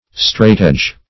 Straightedge \Straight"edge`\, n.